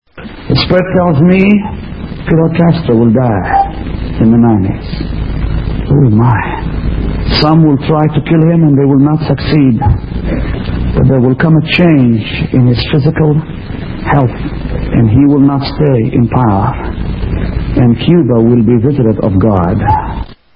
Orlando Christian Center, Dec. 31st, 1989.